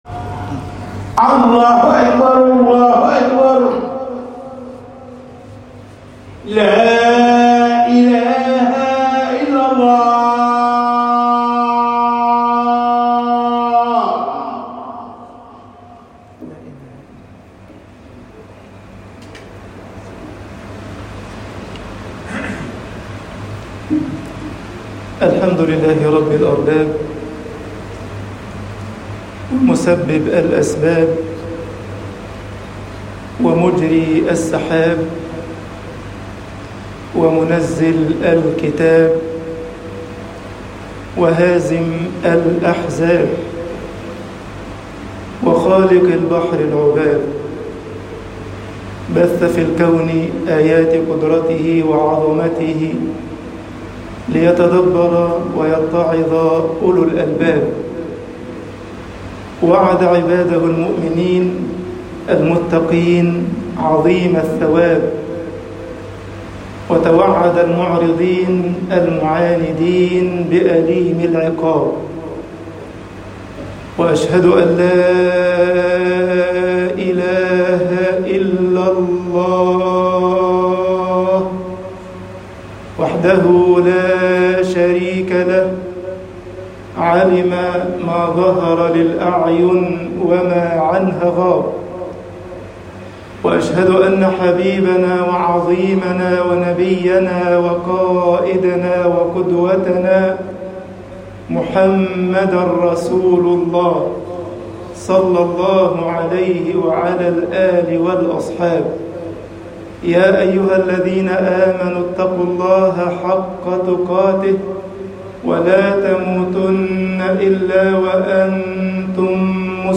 خطب الجمعة